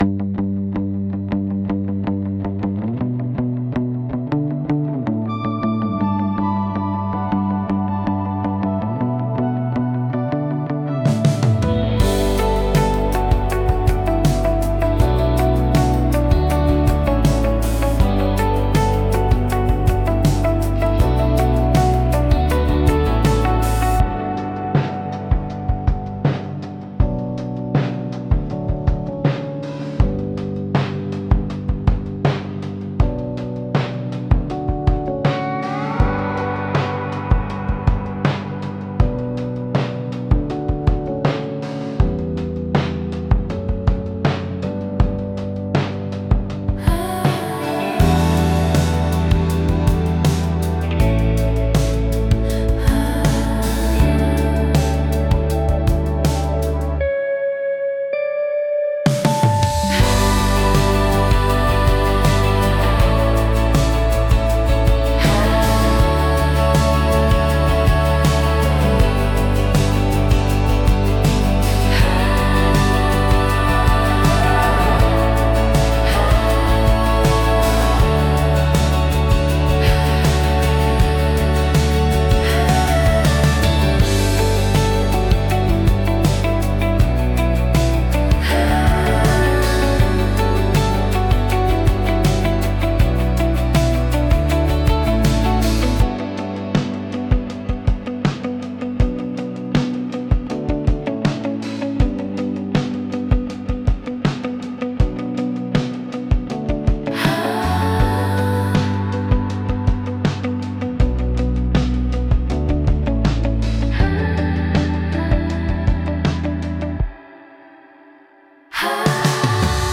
静かで美しい音の重なりが心地よく、感性を刺激しながらも邪魔にならない背景音楽として活用されます。